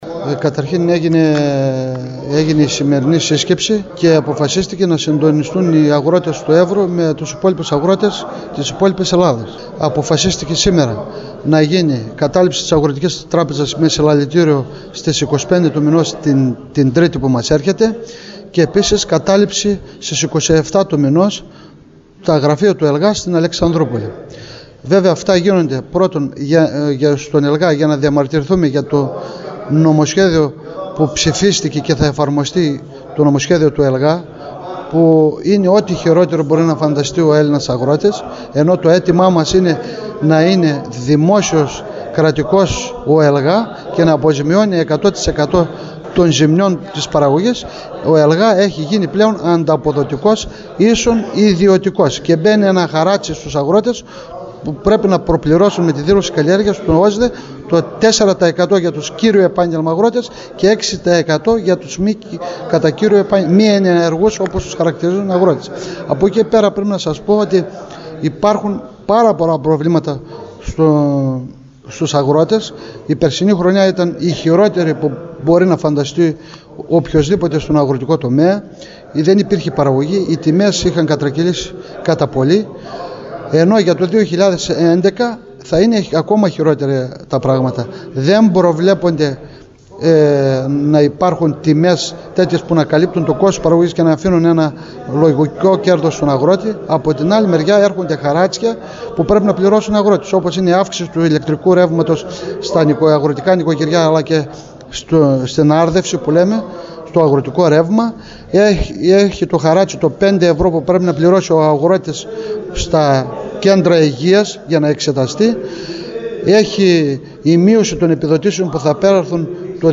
στη χθεσινή σύσκεψη των αγροτών και κτηνοτρόφων στο εργατικό κέντρο της Ορεστιάδας